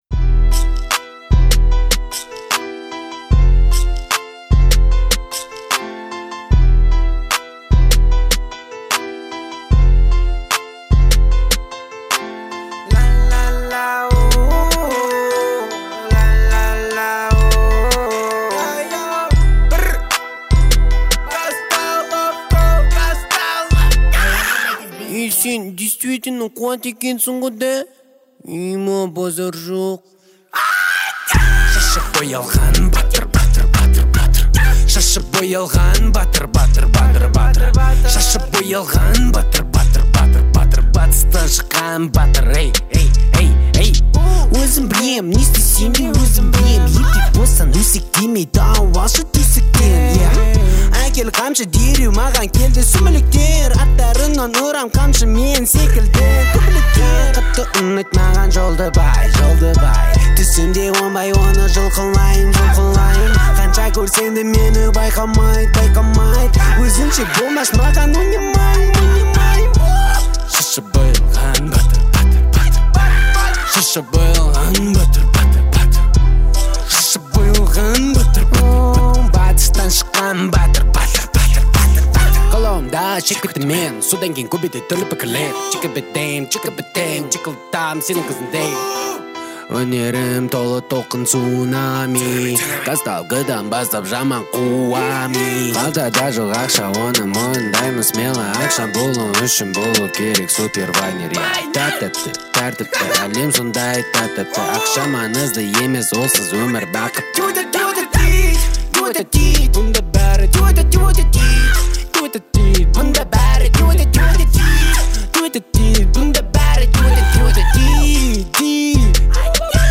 это яркая композиция в жанре казахской народной музыки